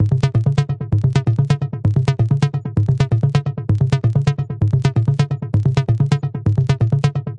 130 Bpm 酸性回声基调
描述：使用Ableton Live 8创建的Detroit Techno bassline。
Tag: 循环 合成器 电子乐 底特律 贝斯 打败 贝斯